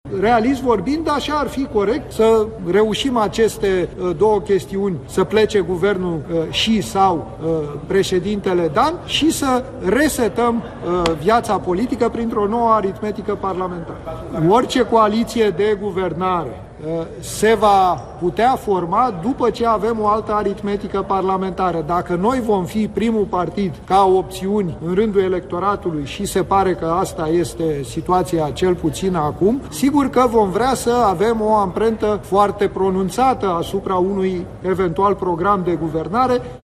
Liderul senatorilor AUR, Petrișor Peiu, într-o conferință de presă: Țara trebuie  administrată, iar noi credem că singurul partid care ar putea să sprijine aceste demersuri este PSD-ul